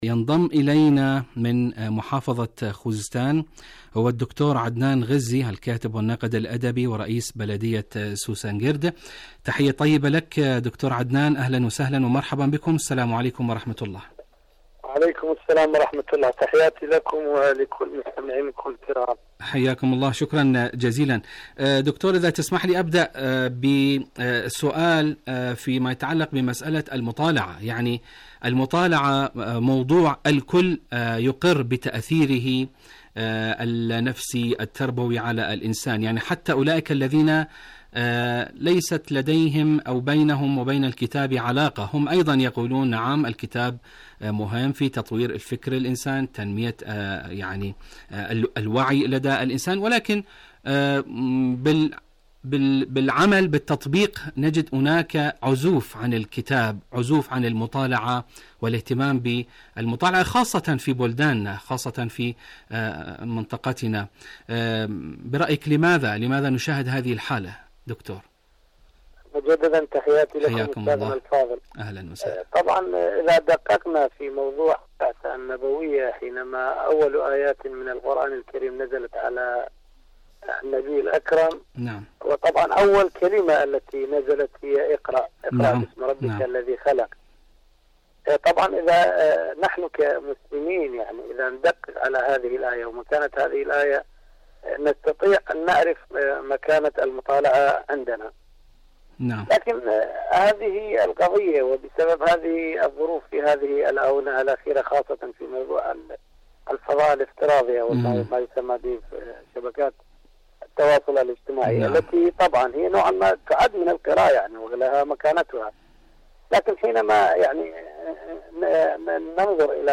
إذاعة طهران- دنيا الشباب: مقابلة إذاعية مع الدكتور عدنان الغزي الكاتب والناقد الأدبي ورئيس بلدية سوسنكرد من إيران حول موضوع كتابي، الجليس المفقود.